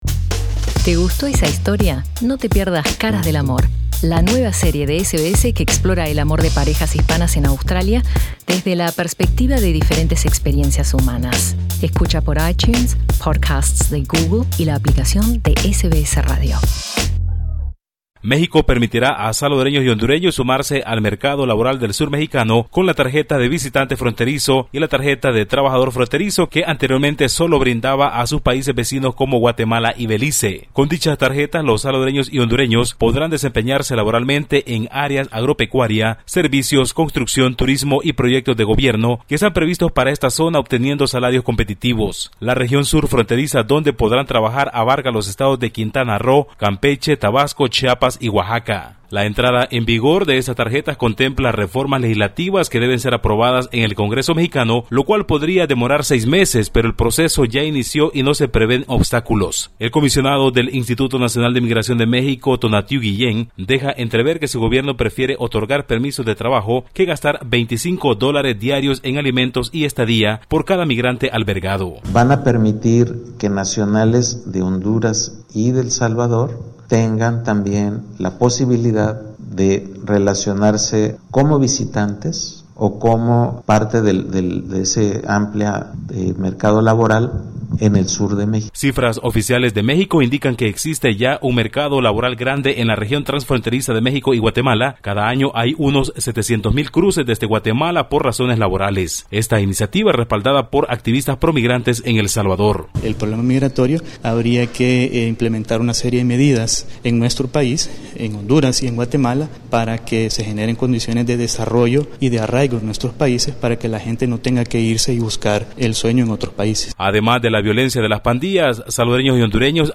Escucha el informe con nuestro corresponsal en Centroamérica